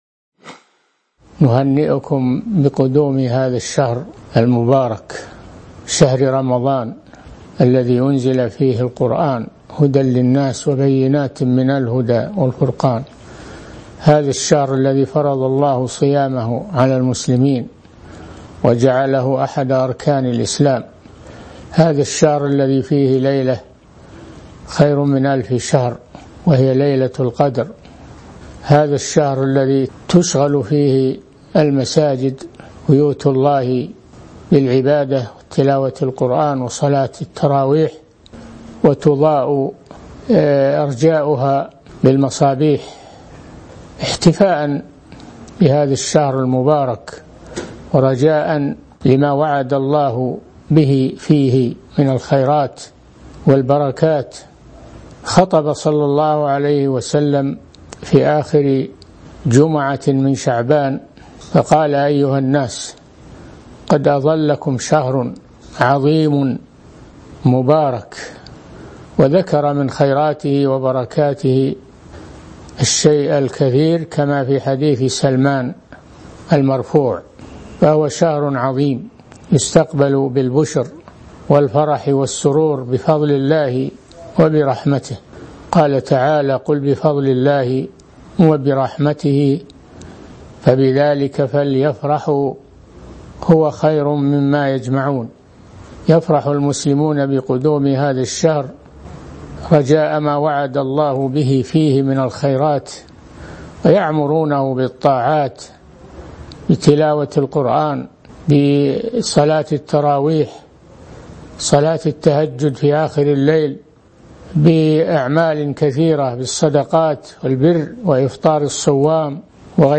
كلمة الشيخ صالح الفوزان بمناسبة قدوم رمضان
من مواعظ أهل العلم